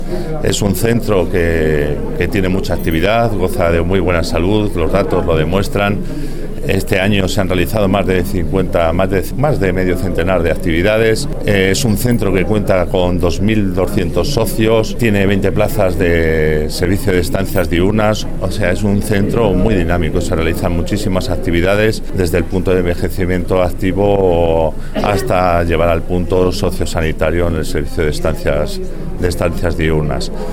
El director provincial de Bienestar Social en Guadalajara, José Luis Vega, habla del Centro de Día Las Acacias